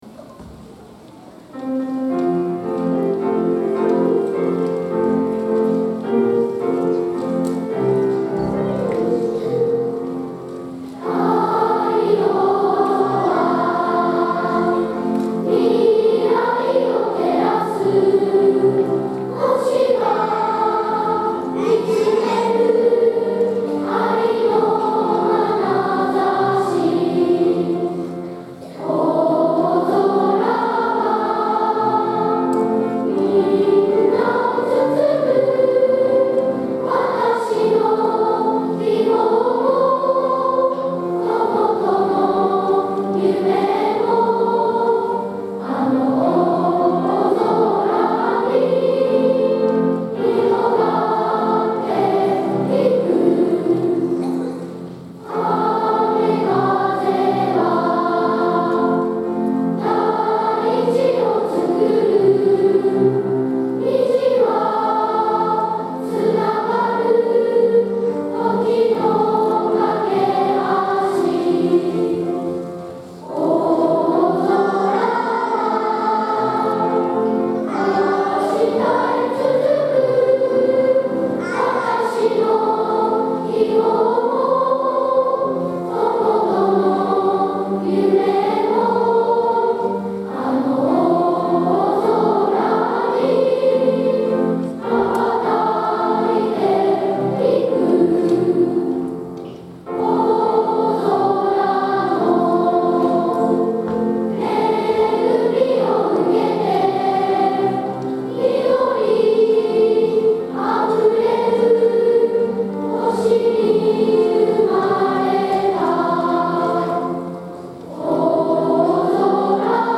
2018年2月24日（土）大空ありがとうコンサート
この校歌を会場のみなさんと一緒に歌い、会場にきれいな歌声を響かせました♪